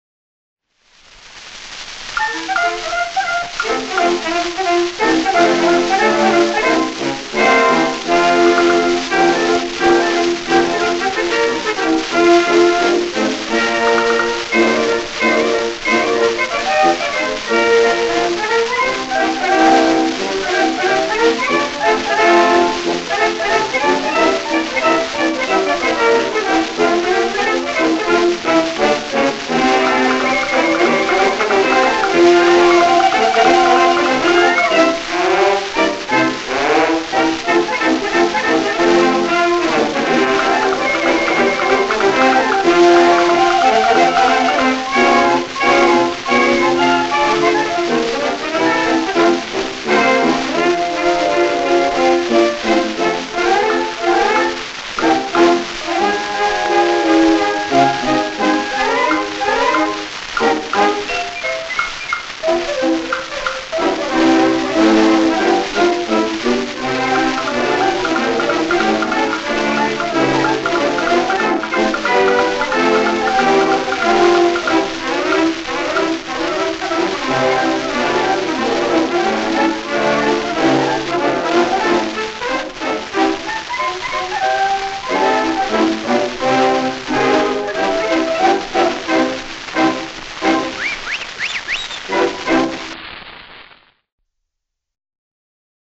Fox-trot) Band